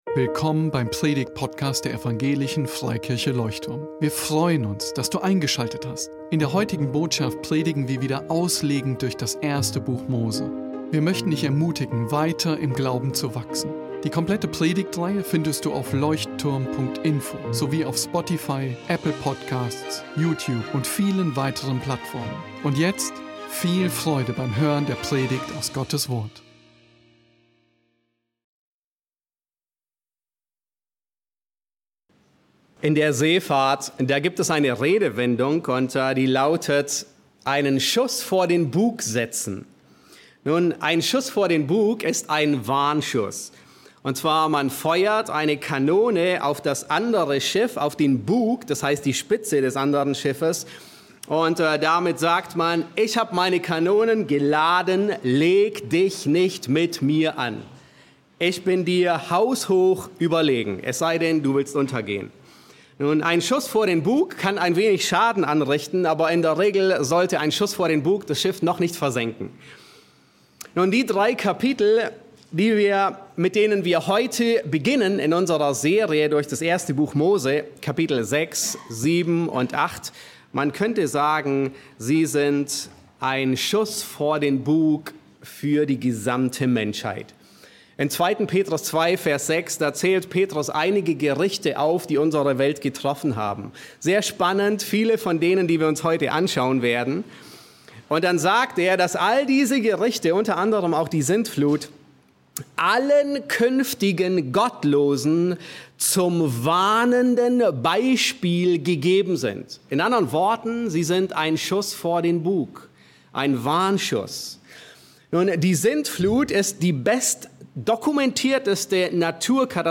Diese Predigt beleuchtet den biblischen Bericht der Sintflut aus 1. Mose 6 und zeigt, warum sie als göttlicher Warnschuss für die Menschheit gilt.